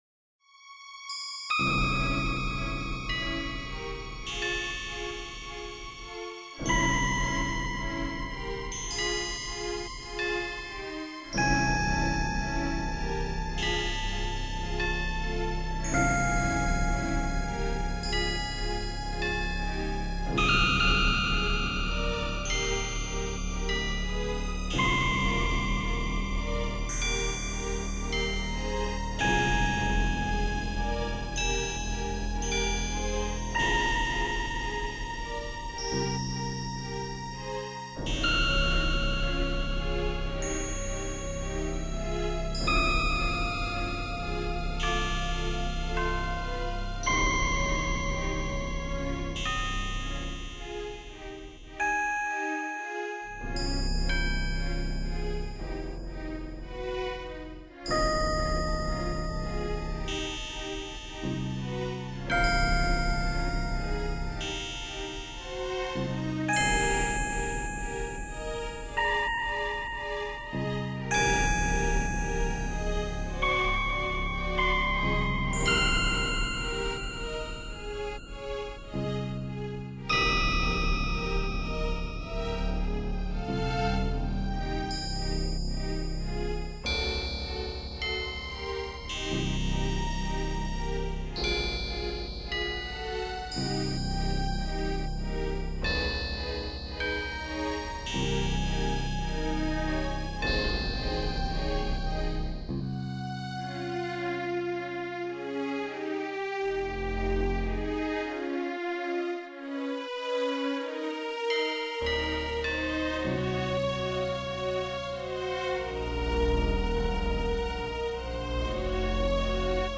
Background Music with some effects, Creepy Beginning.